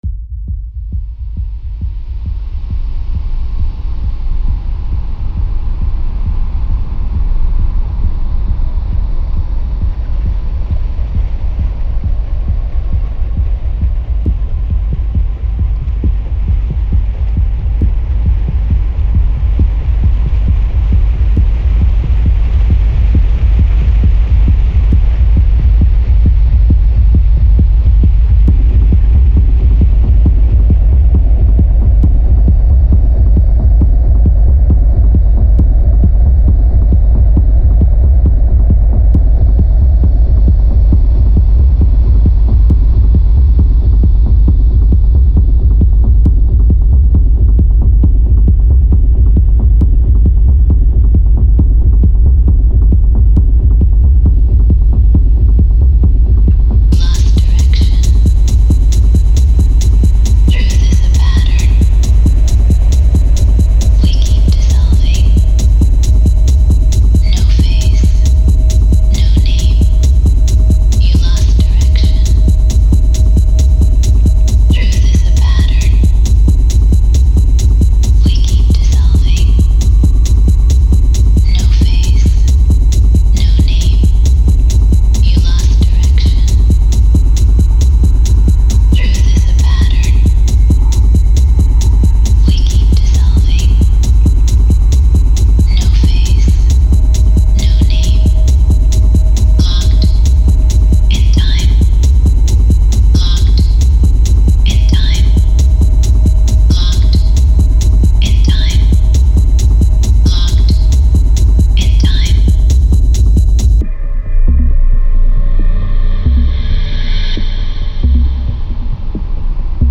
Tags: Techno